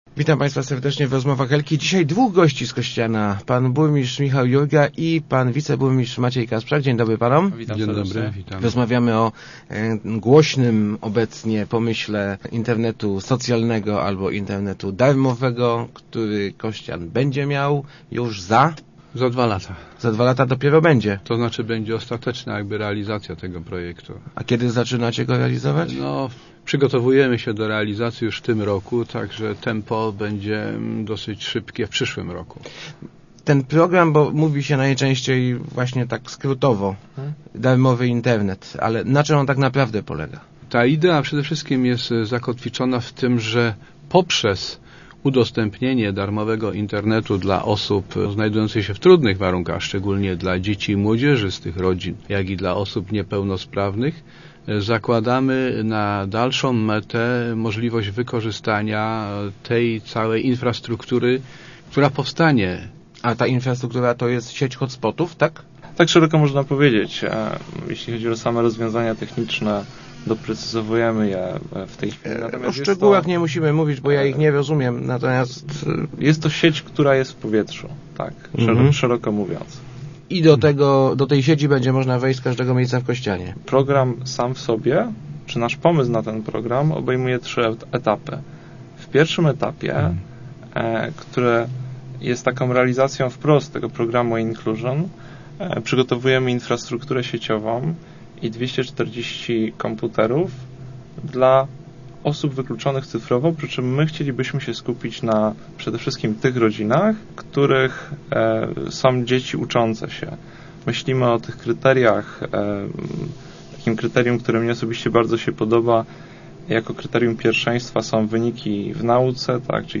Burmistrz Michał Jurga i jego zastępca Maciej Kasprzak przekonywali w Rozmowach Elki, że Internet socjalny, który już za dwa lata w Kościanie będzie funkcjonował, ma jeszcze kilka innych zastosowań, jednak najważniejszym jest właśnie wyrównywanie szans edukacyjnych.